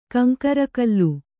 ♪ kaŋkarakallu